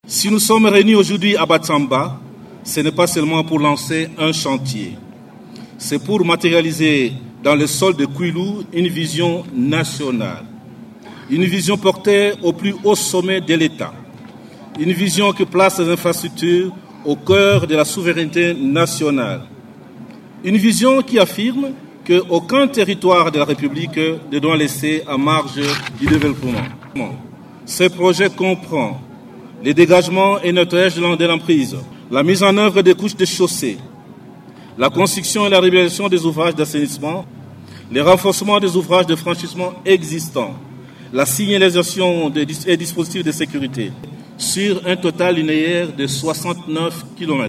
Écoutez un extrait du discours de Nico Nzau Nzau: